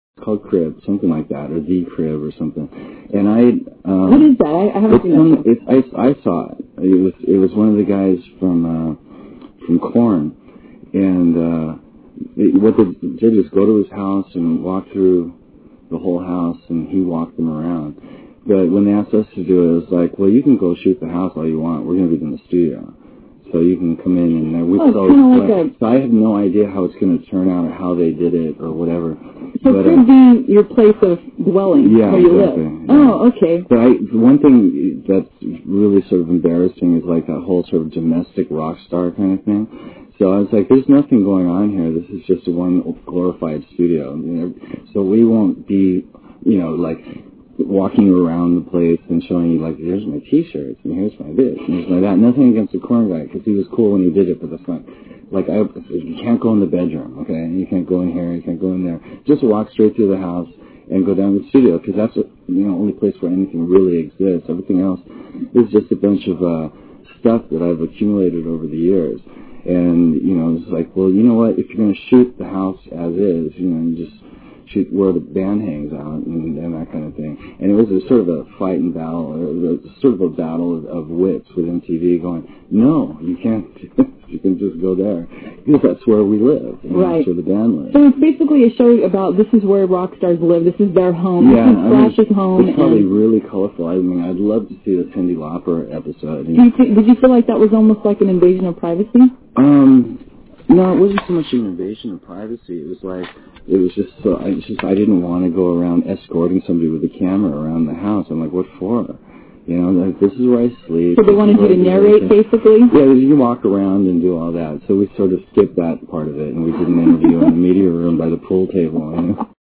KNAC Interviews SLASH 7/20/00